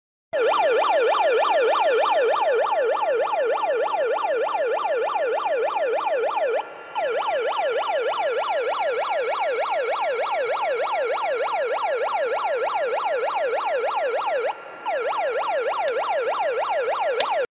Alertamiento con megáfono
Alertamiento-Emergencia-con-Megafono.mp3